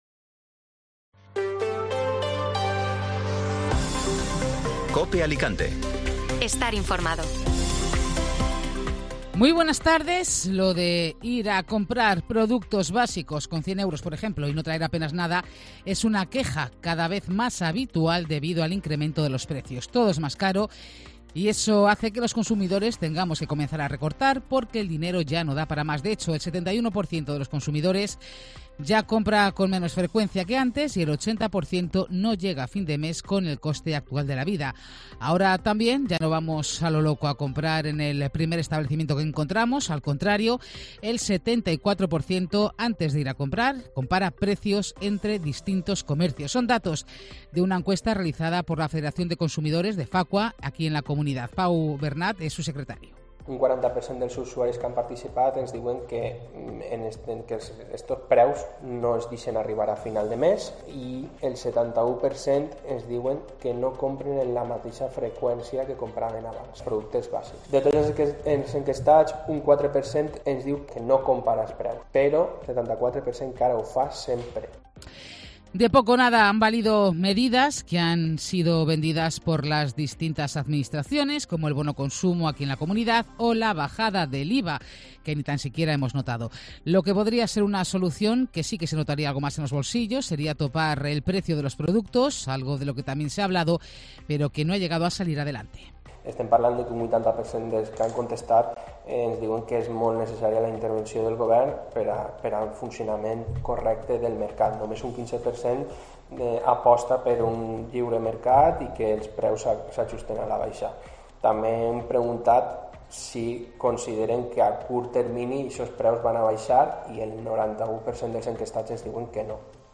Informativo Mediodía Cope Alicante ( Miércoles 7 DE JUNIO)